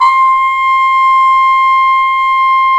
Index of /90_sSampleCDs/Roland L-CD702/VOL-2/BRS_Cup Mute Tpt/BRS_Cup Mute Dry